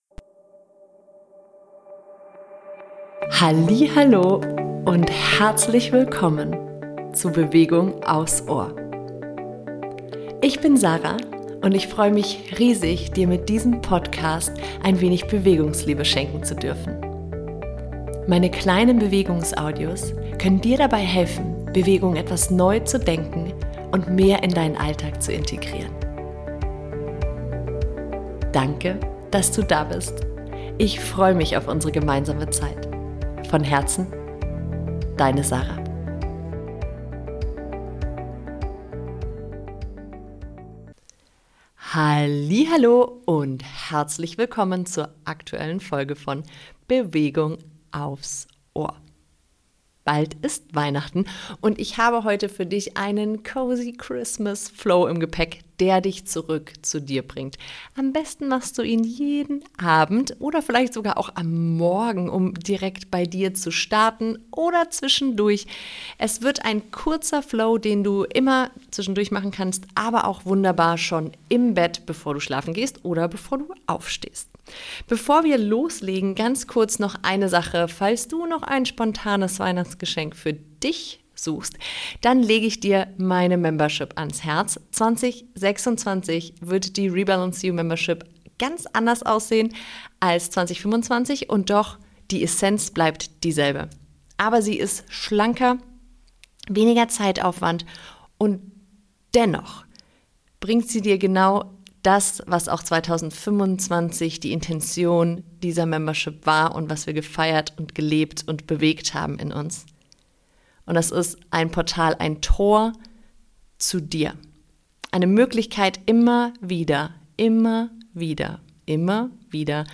Cozy Weihnachtsflow - deine Mini Auszeit im Festtagstrubel - MIT MUSIK ~ Bewegung aufs Ohr Podcast